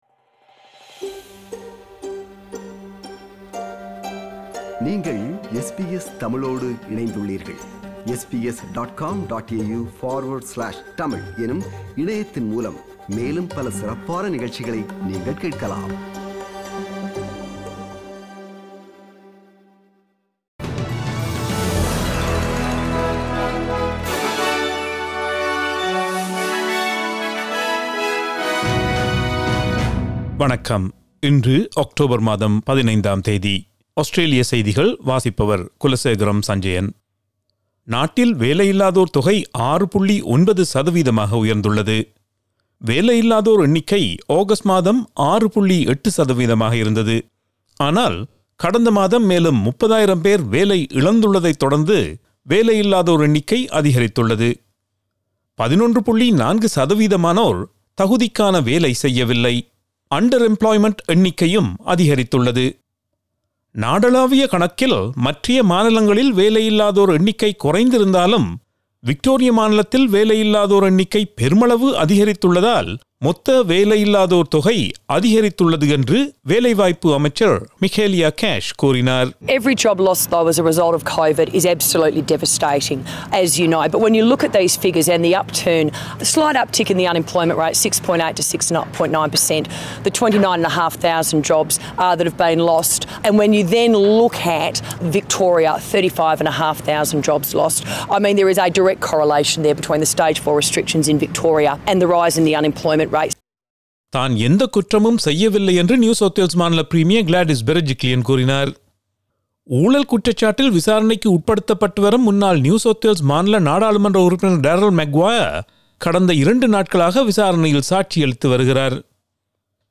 Australian news bulletin for Thursday 08 October 2020.